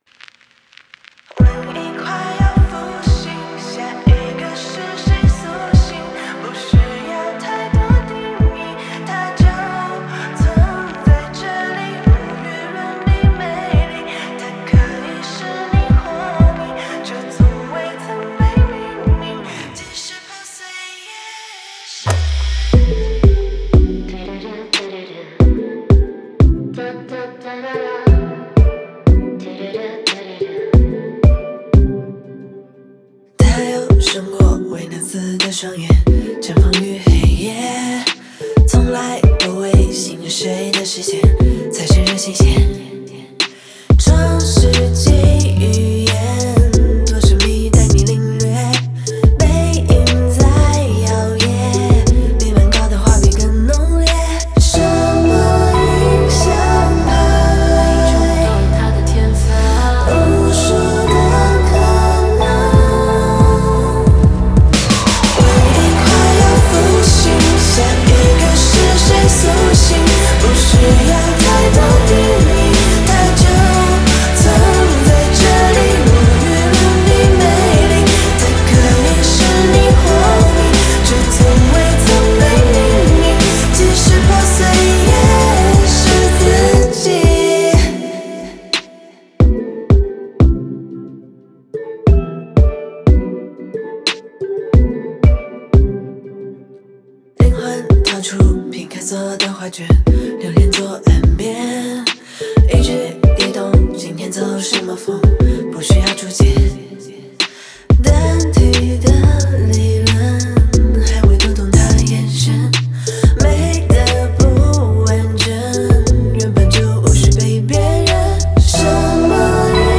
Ps：在线试听为压缩音质节选，体验无损音质请下载完整版
吉他Guitar
贝斯Bass
和声Backing Vocal